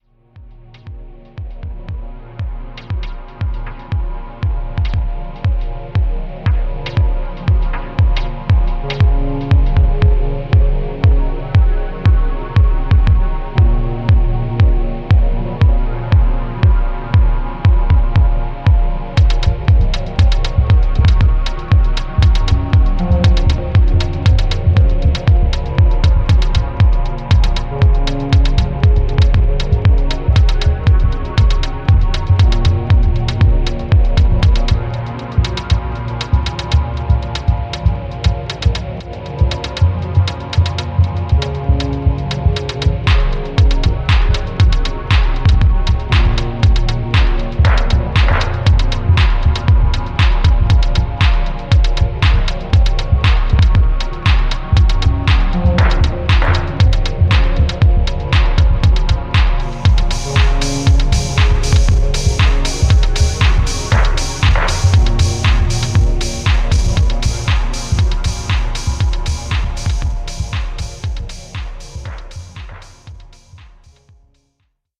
Deep & raw techno debut ep by this Milanese producer.
House Techno